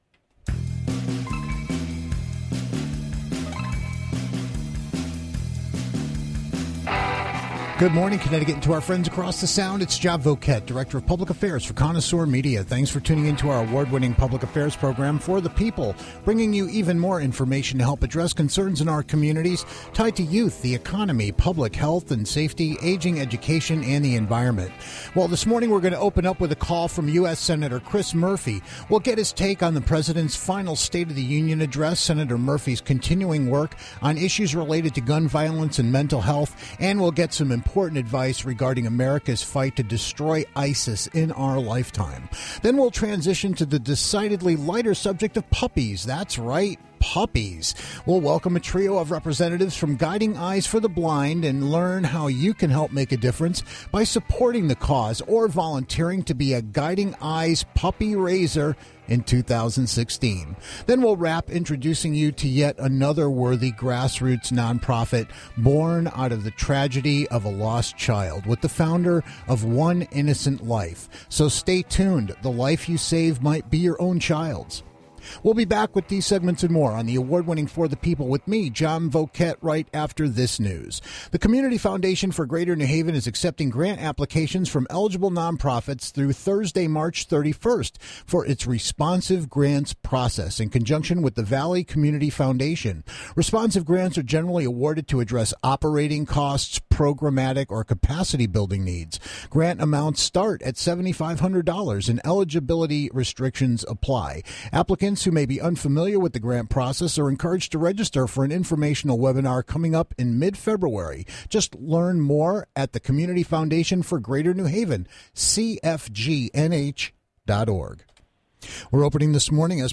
checks in with US Senator Chris Murphy for a quick-moving chat covering the President's State of the Union Address, misconceptions about the fight against ISIS, as well as progress on gun violence and mental health reforms. Then we'll transition to the decidedly lighter subject of puppies - that's right - puppies, with a trio of representatives from Guiding Eyes for the Blind.